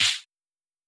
Shaker Groovin 1.wav